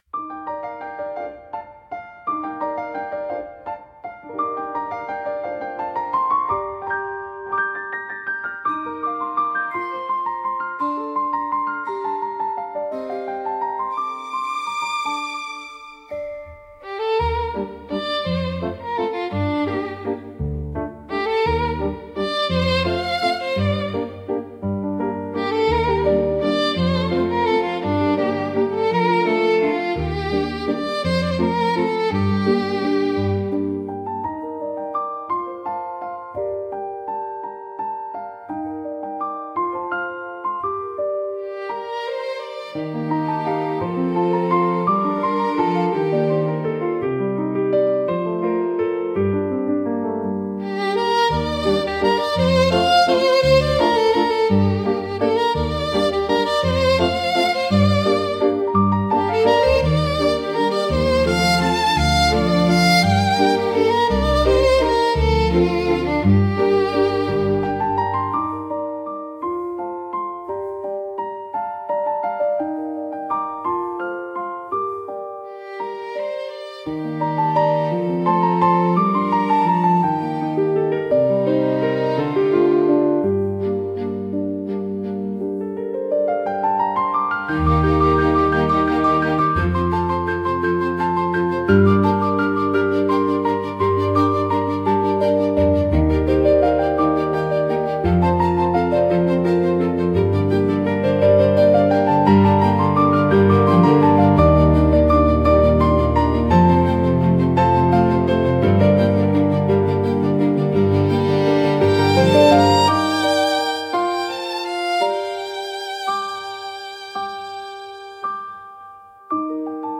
「明るい」